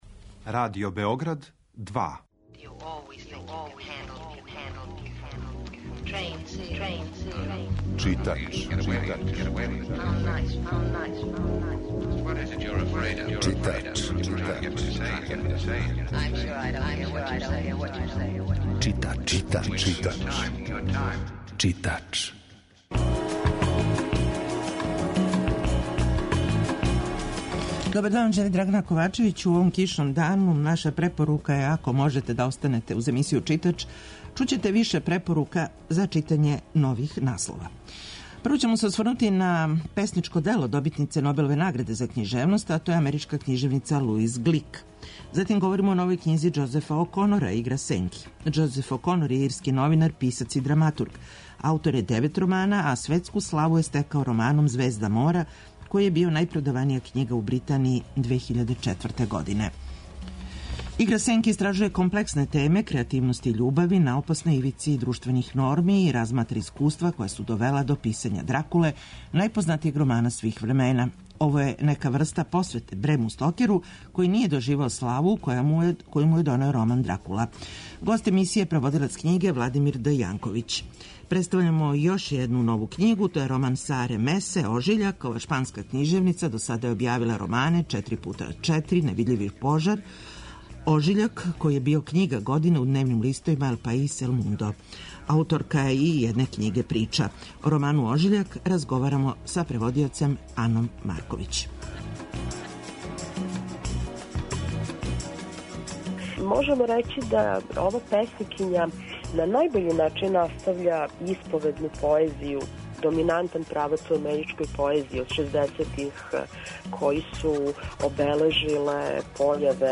Емисија је колажног типа, али је њена основна концепција – прича о светској књижевности
Аудио подкаст Радио Београд 2